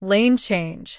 audio_lane_change.wav